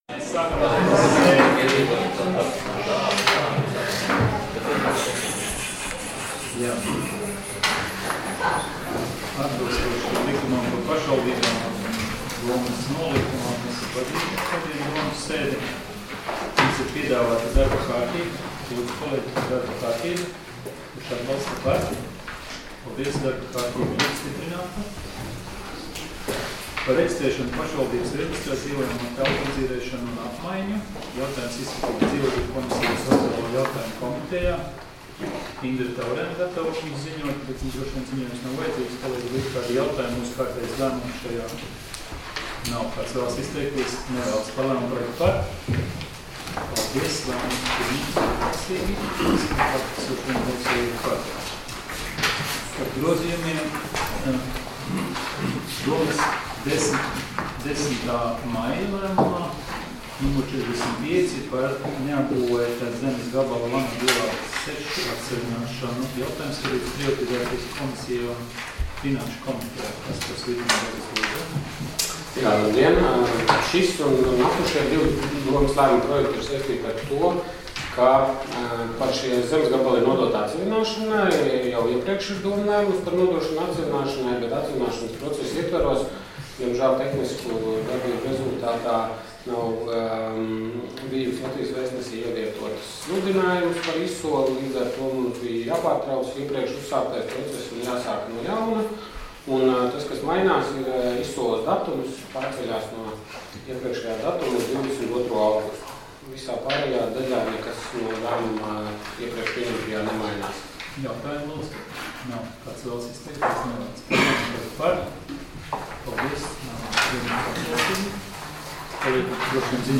Domes sēdes 12.07.2019. audioieraksts